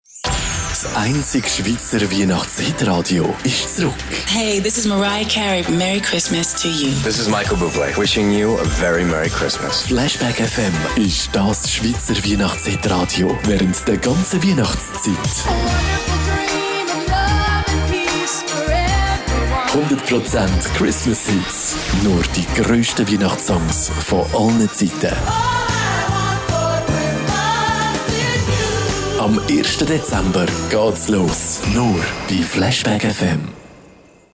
Sponsoring Show
Programmtrailer Christmas Radio Flashback FM.mp3